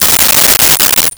Coins Thrown 01
Coins Thrown 01.wav